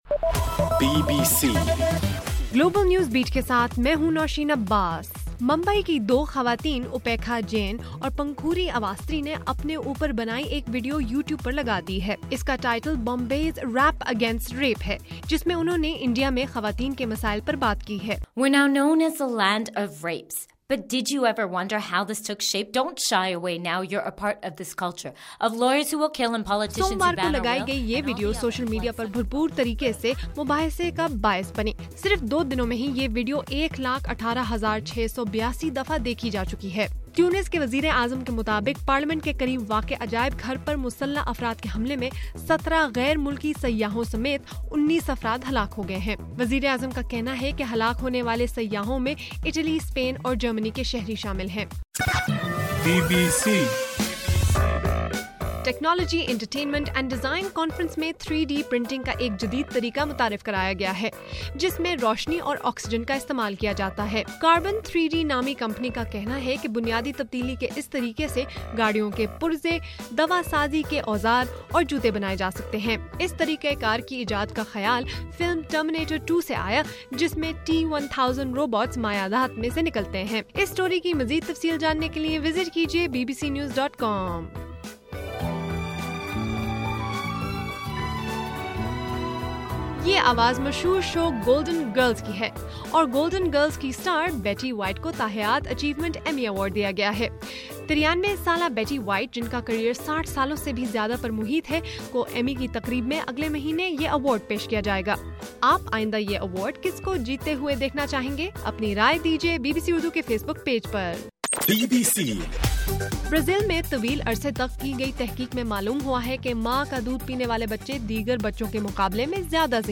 مارچ 18: رات 11 بجے کا گلوبل نیوز بیٹ بُلیٹن